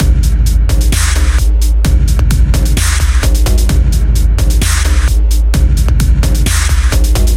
标签： 130 bpm Electronic Loops Drum Loops 1.24 MB wav Key : Unknown
声道立体声